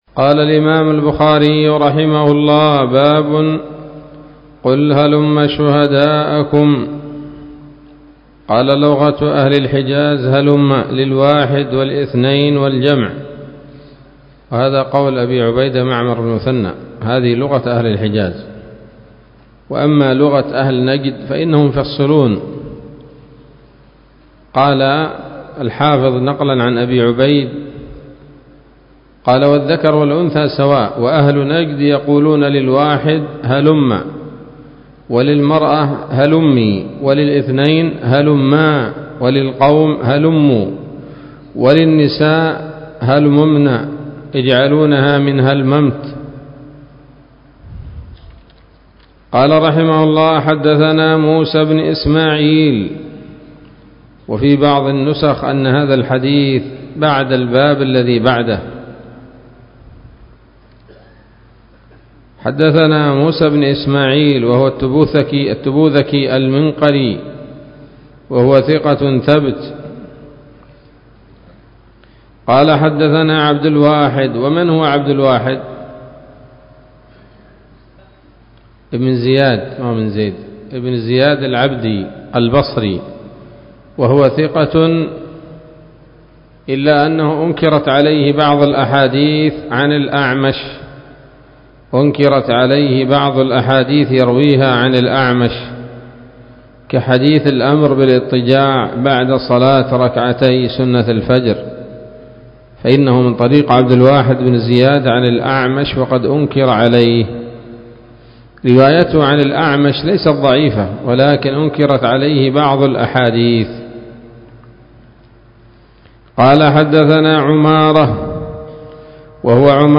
الدرس الخامس بعد المائة من كتاب التفسير من صحيح الإمام البخاري